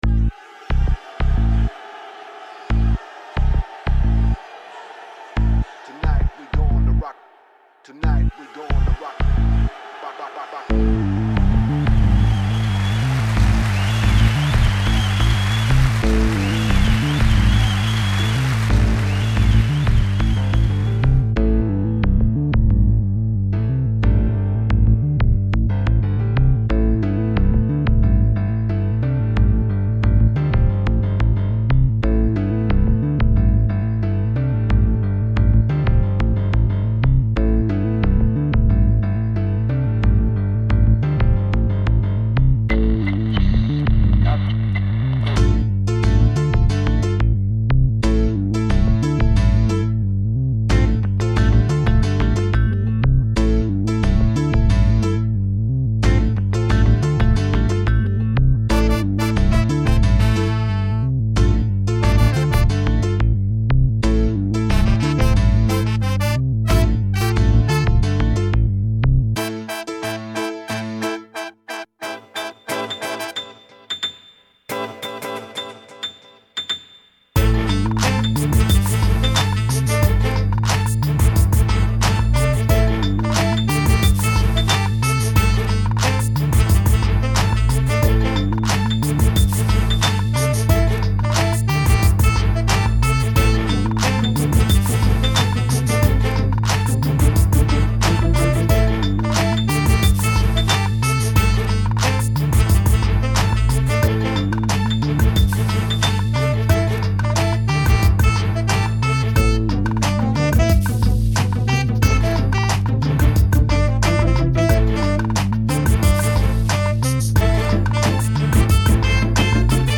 The name comes from the fact that each musical segment in the track uses different orchestral palettes to reflect the different kinds moods and energy people tend to have as they start out young and eventually age. The score starts off 'young' and eventually ends up 'old'. It's a rather cynical piece - not that I'm cynical, but cynicism can make for good music.